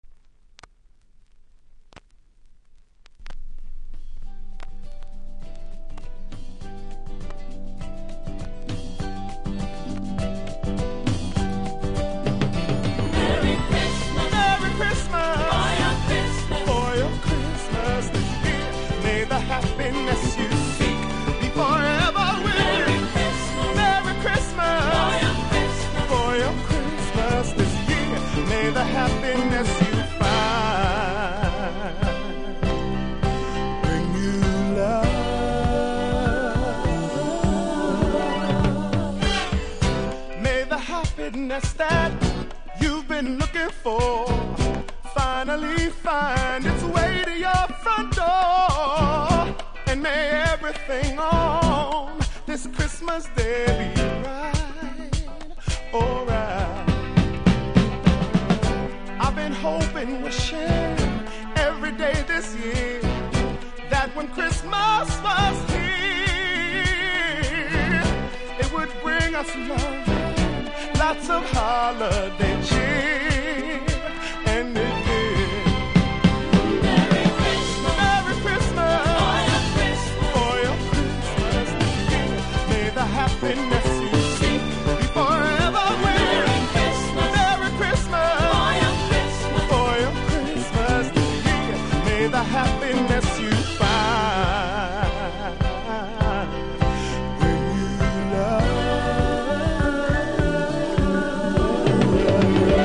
Vinyl
イントロ、途中とノイズ感じますがプレイは問題無いレベル。